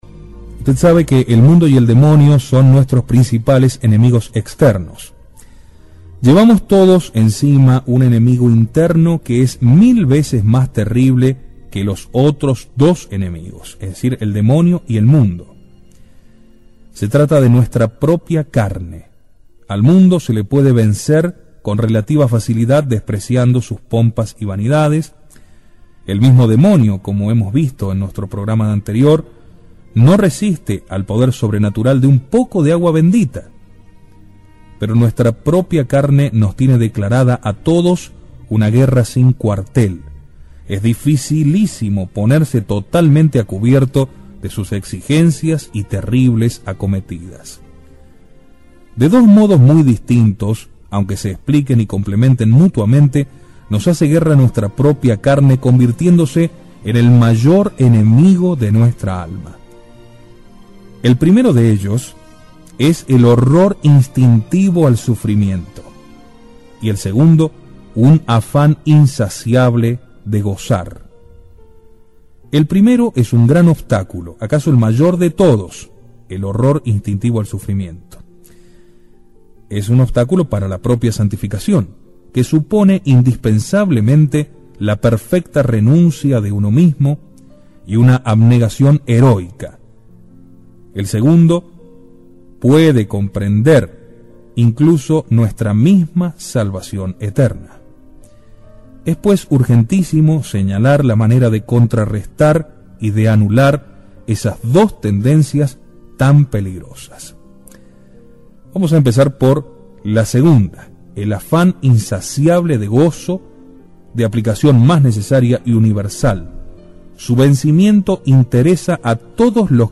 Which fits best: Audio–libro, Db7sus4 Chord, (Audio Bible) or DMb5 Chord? Audio–libro